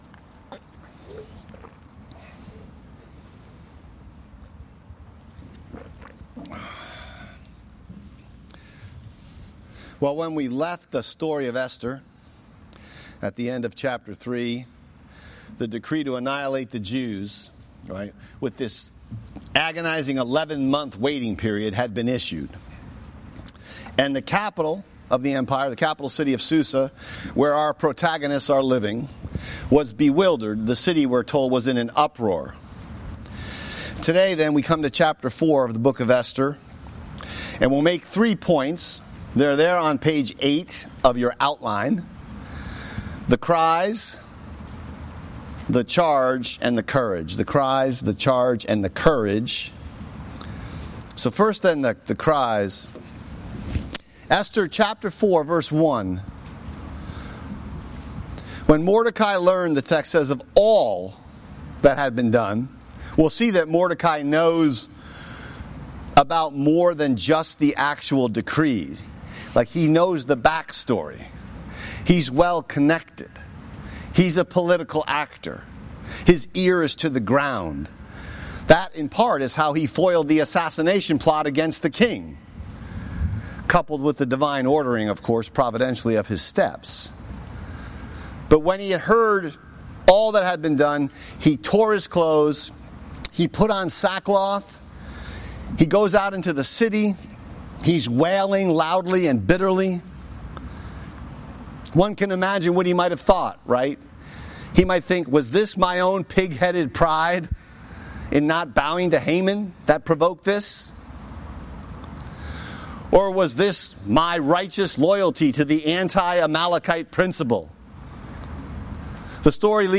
Message text: Esther 4:1-7